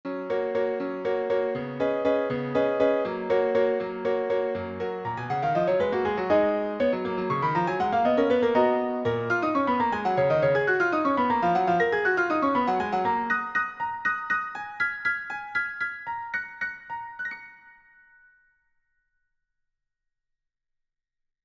my first piano piece